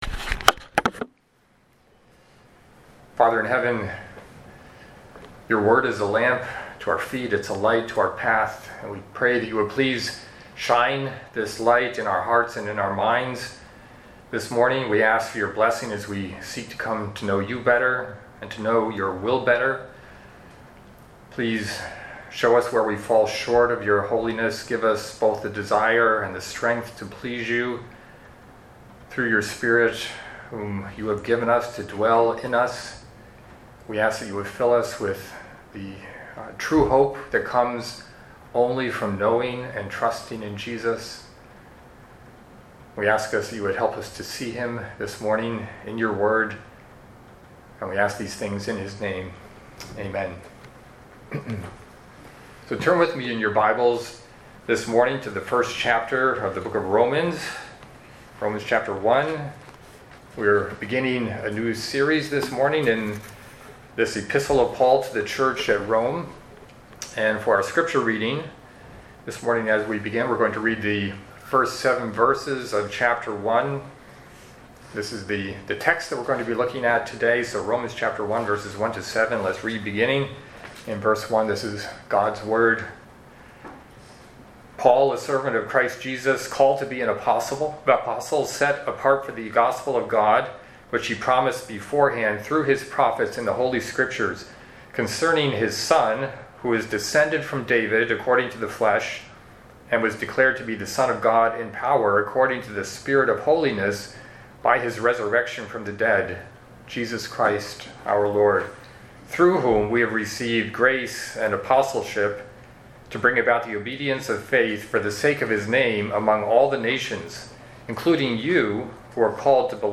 Sermons – Ardsley Bible Chapel